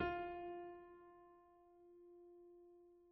/piano/F4.mp3